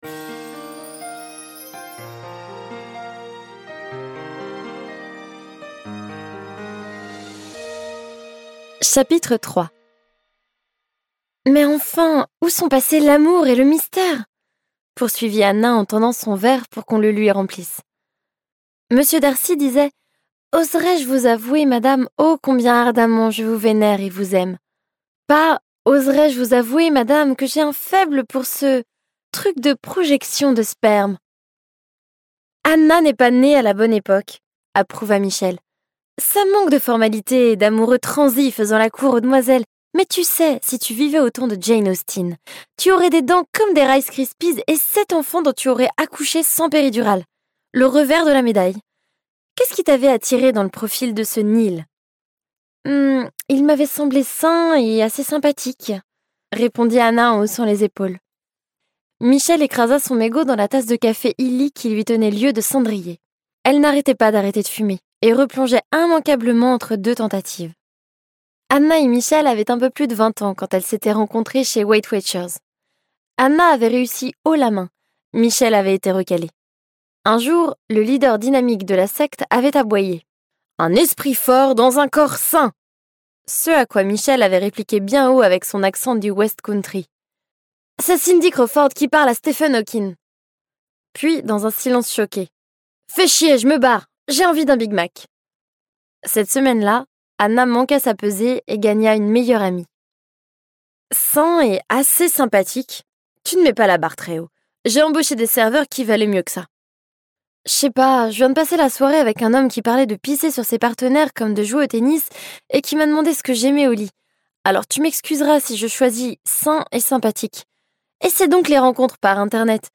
» - Kirkus Ce livre audio est interprété par une voix humaine, dans le respect des engagements d'Hardigan.